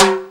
44_22_tom.wav